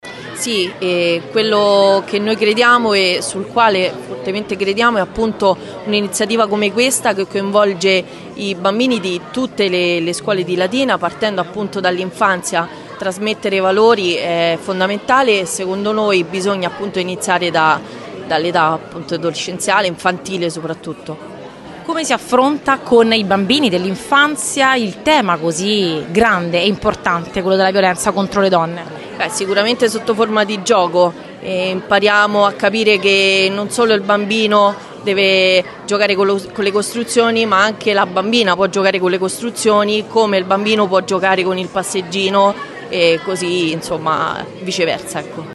LATINA –  Si sono alternati  momenti dedicati alle  premiazioni del concorso Educare al rispetto e momenti di approfondimento sul tema della prevenzione della violenza di genere, nell’evento tematico promosso dal Comune di Latina per il 25 novembre che si è svolto al Teatro Ponchielli di Latina.
L’assessore all’Istruzione Francesca Tesone